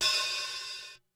Index of /90_sSampleCDs/300 Drum Machines/Korg DSS-1/Drums01/06
HiHatOp.wav